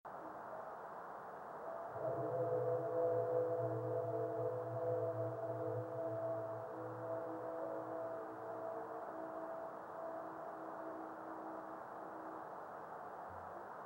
Observer's comments:  Another good sonic specimen with stereo definition.
Short but interesting sonic reflection.